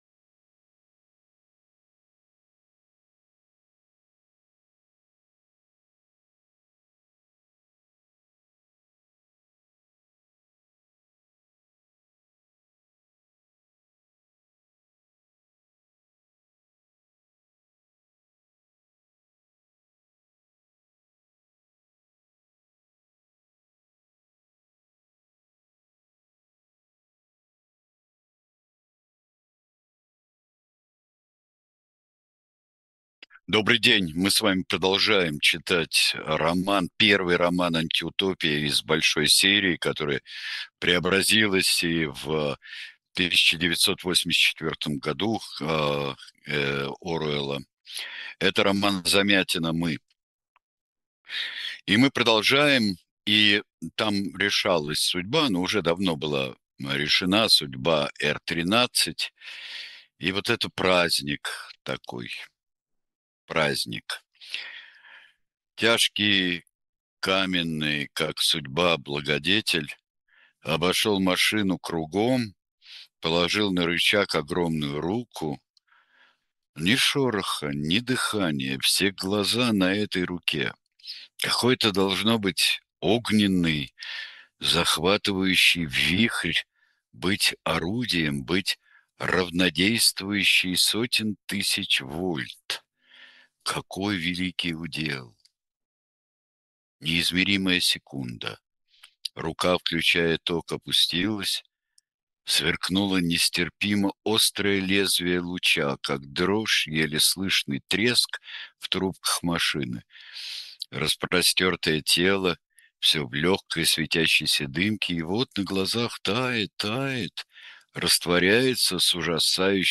Читает Сергей Бунтман